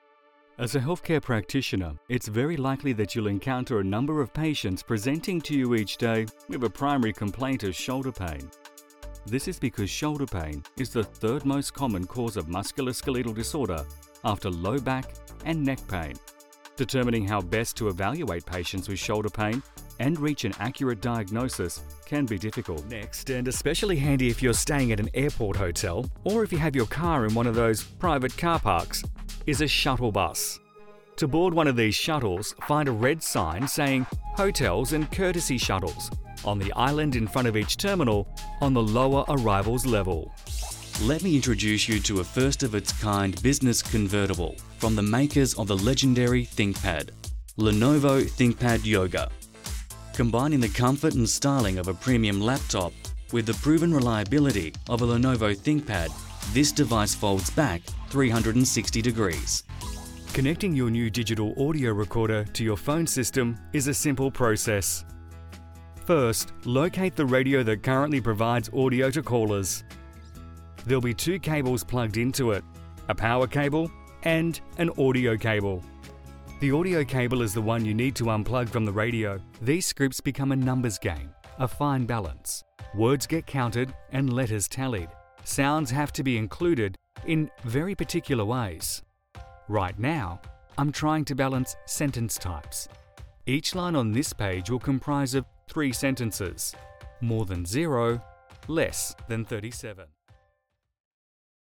Yng Adult (18-29) | Adult (30-50)